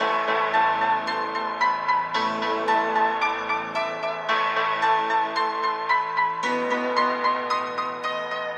Tag: 112 bpm Cinematic Loops Pad Loops 1.44 MB wav Key : Unknown